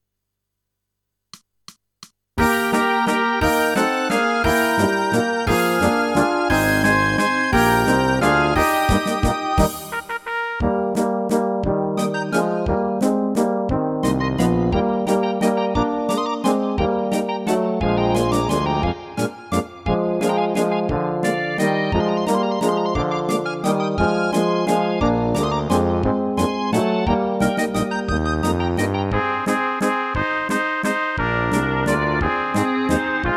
Rubrika: Národní, lidové, dechovka
- valčík